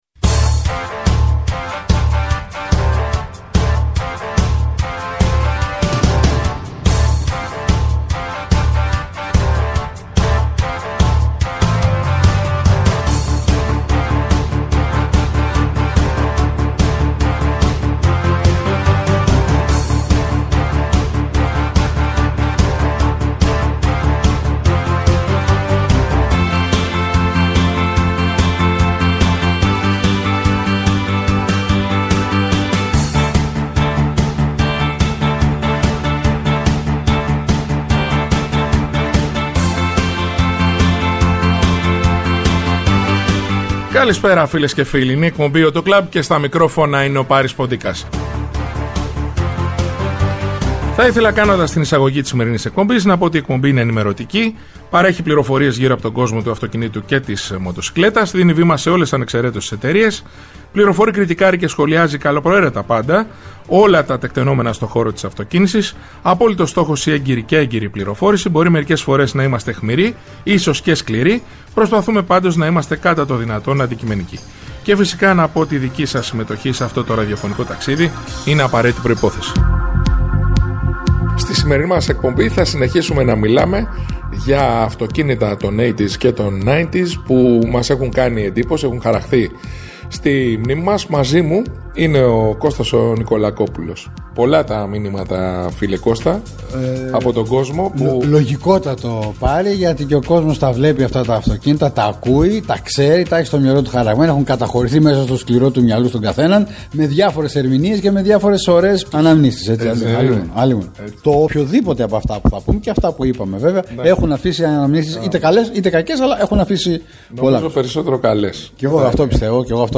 Η εκπομπή «AUTO CLUB» είναι ενημερωτική, παρέχει πληροφορίες γύρω από τον κόσμο του αυτοκινήτου και της μοτοσικλέτας, δίνει βήμα σε ολες ανεξεραίτως τις εταιρείες, φιλοξενεί στο στούντιο ή τηλεφωνικά στελέχη της αγοράς, δημοσιογράφους αλλά και ανθρώπους του χώρου. Κριτικάρει και σχολιάζει καλοπροαίρετα πάντα όλα τα τεκτενόμενα στο χώρο της αυτοκίνησης, με απόλυτο στόχο την έγκαιρη και έγκυρη πληροφόρηση για τους ακροατές, με «όπλο» την καλή μουσική και το χιούμορ.